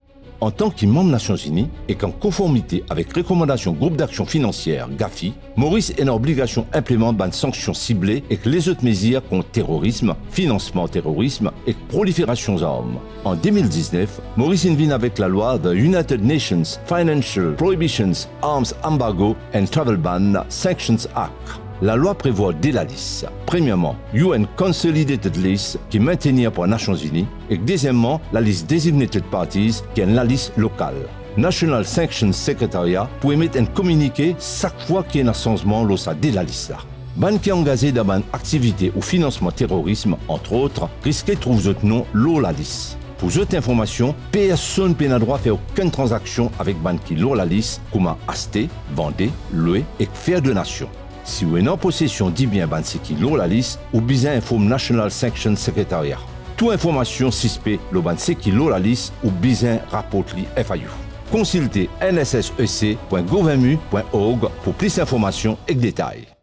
Radio Spot.wav